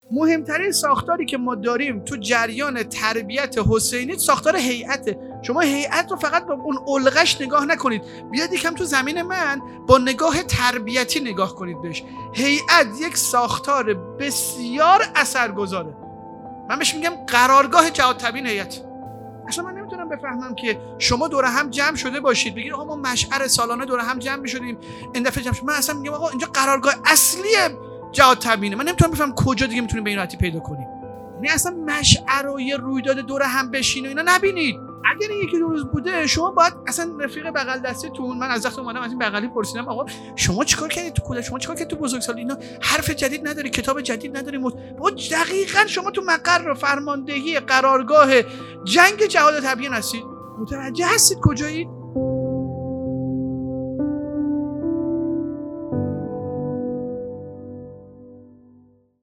پانزدهمین همایش هیأت‌های محوری و برگزیده کشور | جامعه ایمانی مشعر